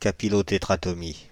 Ääntäminen
France (Île-de-France): IPA: /ka.pi.lo.te.tʁa.tɔ.mi/